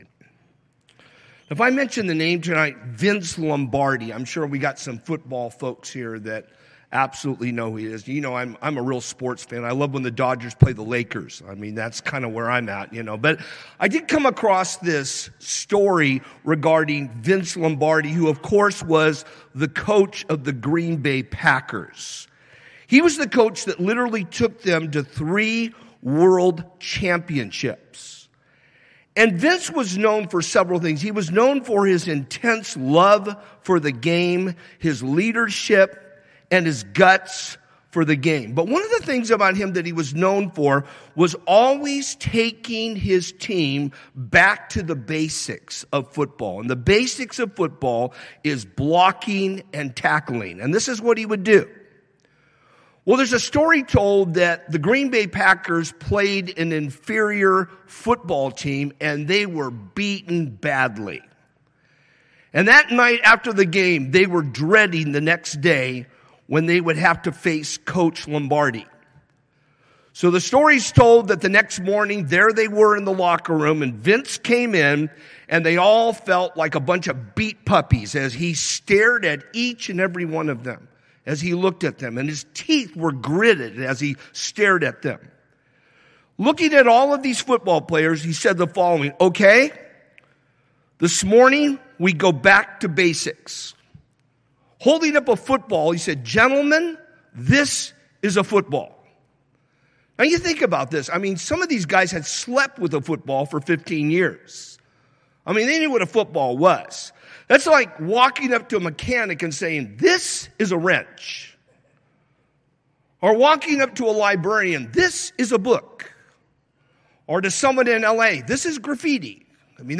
Calvary Chapel Rialto – Sermons and Notes